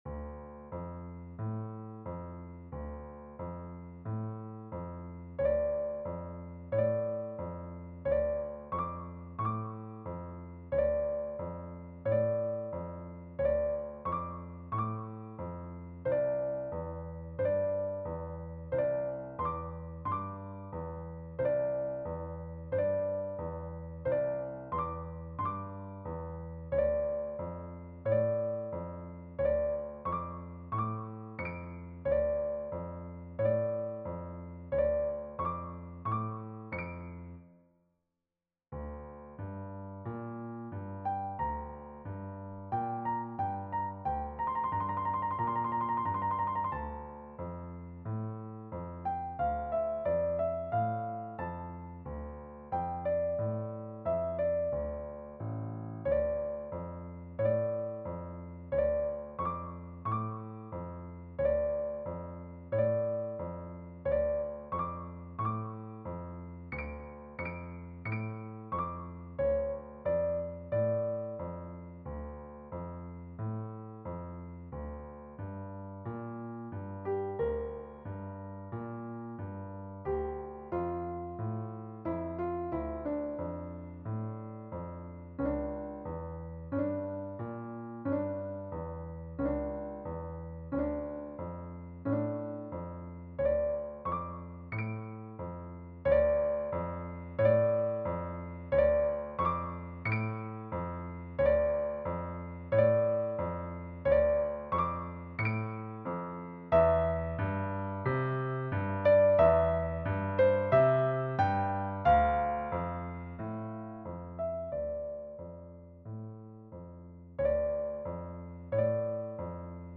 The Year: A Suite for Solo Piano